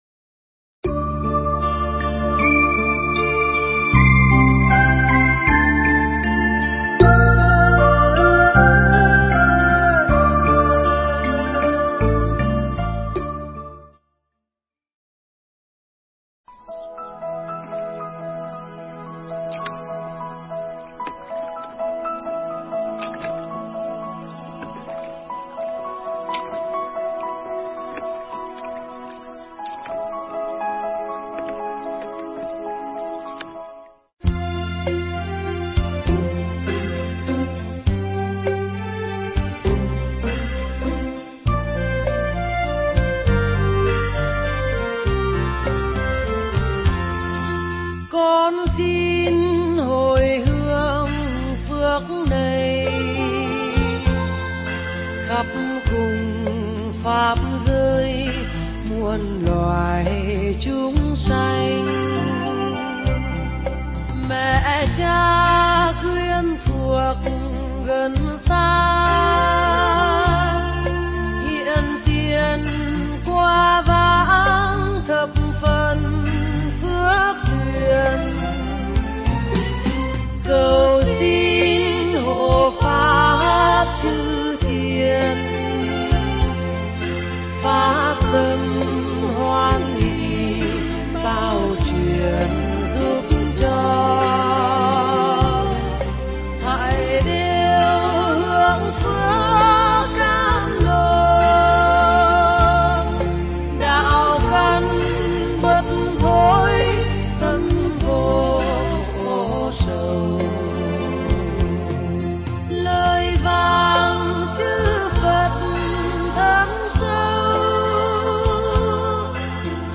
Nghe Mp3 thuyết pháp Sử Dụng Kho Báu Phần 2